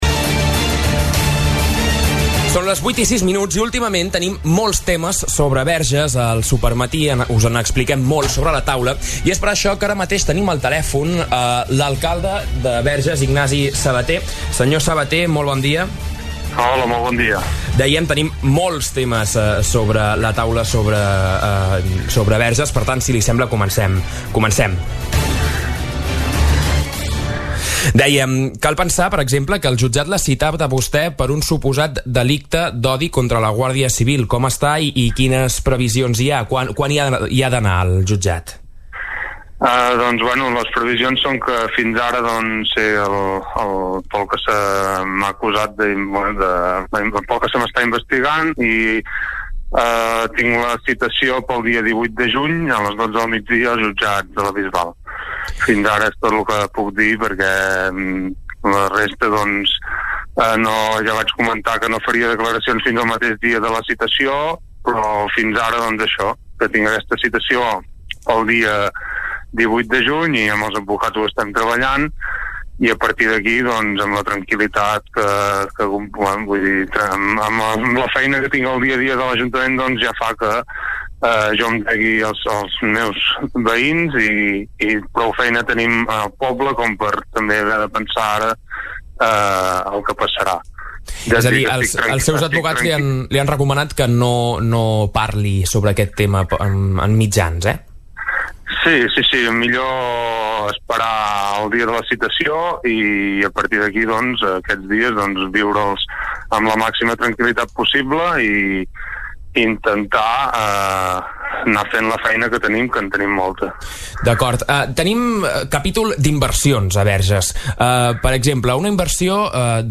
Ho ha explicat l’Alcalde, Ignasi Sabater al Supermatí de Ràdio Capital i n’ha detallat les primeres passes que apel·len a la ciutadania, que s’haurà de pronunciar abans de l’inici de la redacció de la norma.